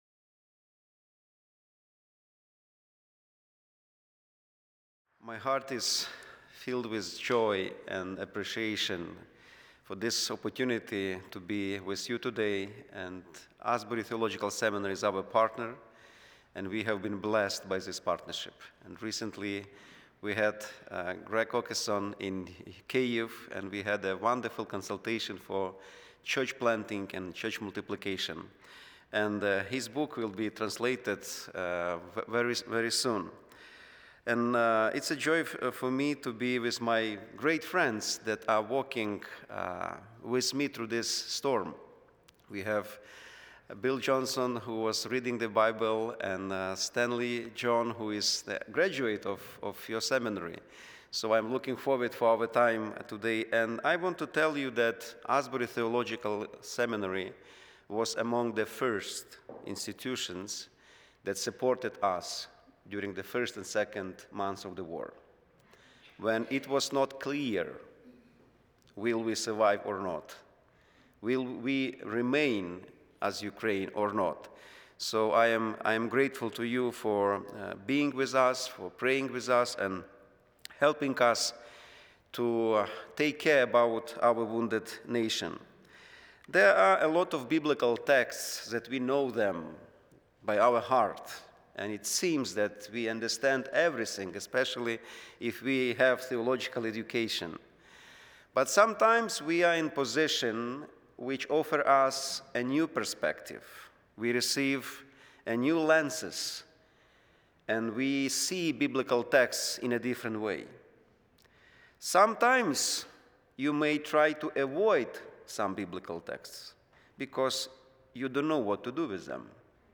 The following service took place on Thursday, January 29, 2026.